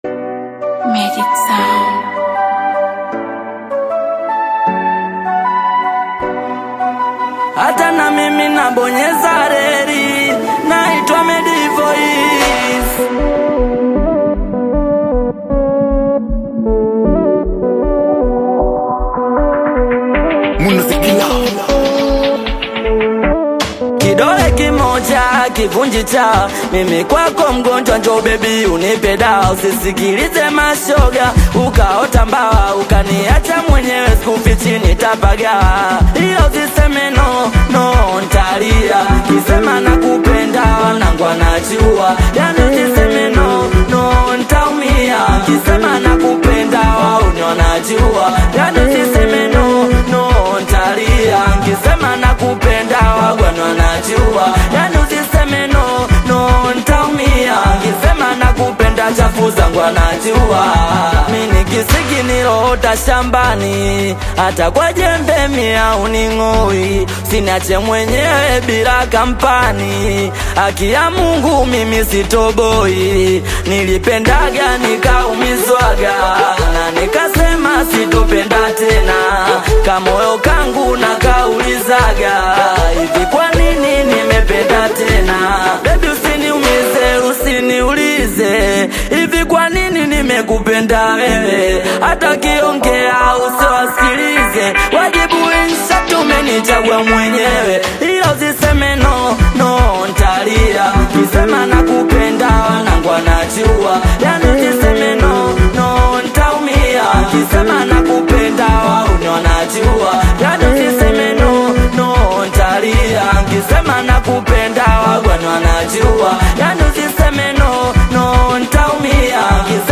high-energy Singeli/Afro-Pop single